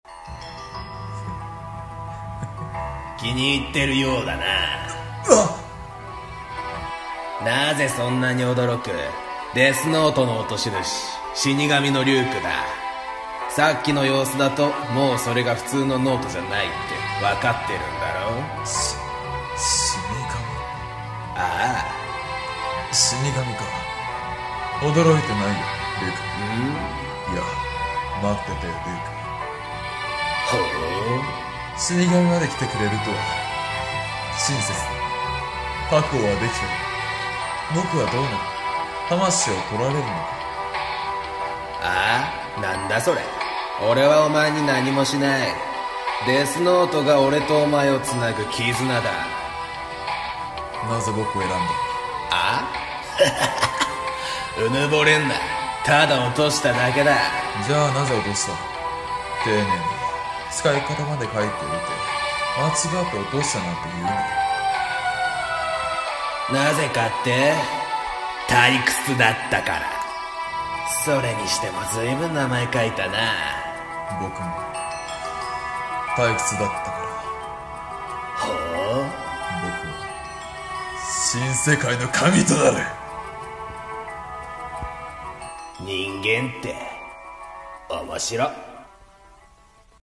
DEATH NOTE 声劇